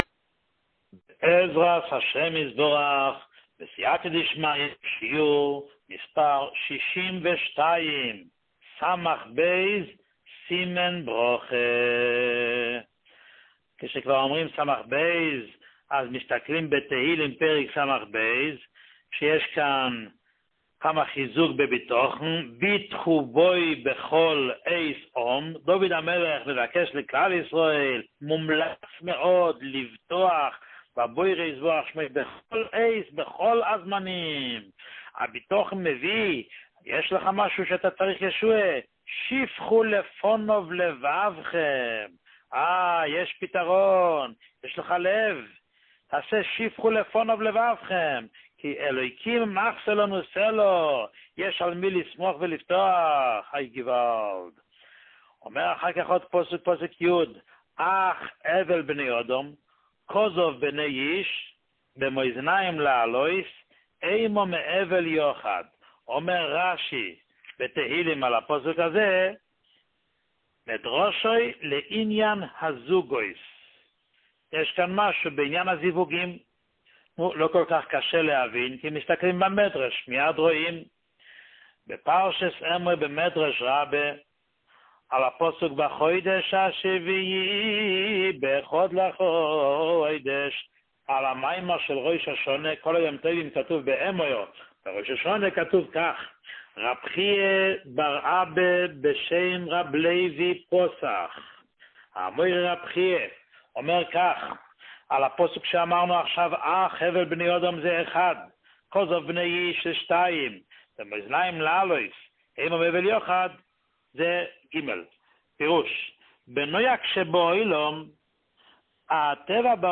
שיעור 62